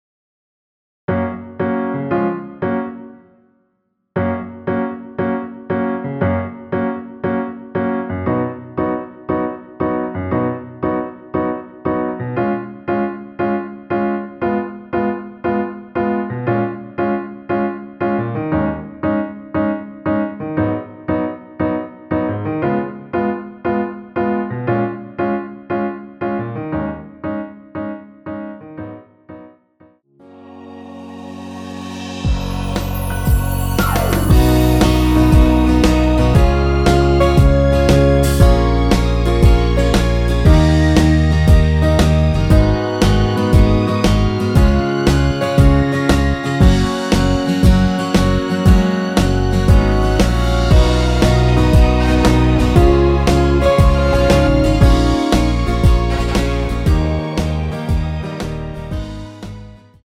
전주 없이 시작하는 곡이라 전주 만들어 놓았습니다.(미리듣기 참조)
앞부분30초, 뒷부분30초씩 편집해서 올려 드리고 있습니다.